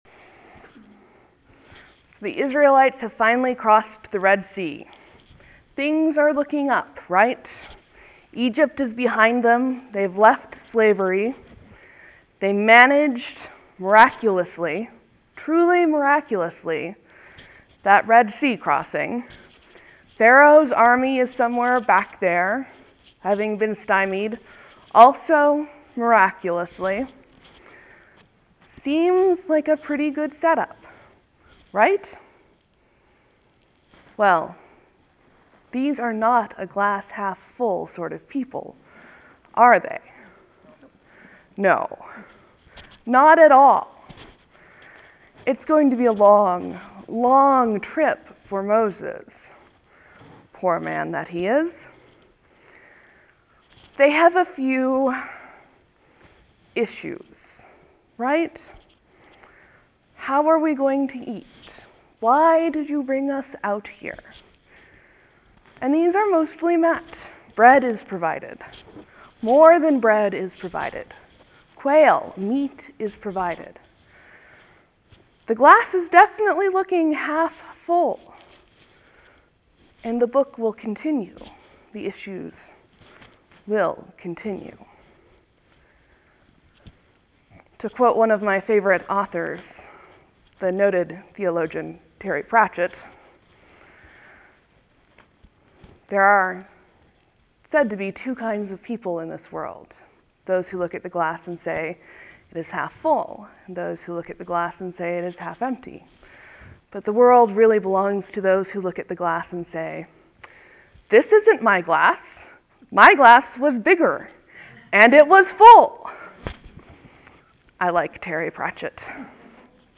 Sermon, Leave a comment
(There will be a few moments of silence before the sermon starts. Thank you for your patience.)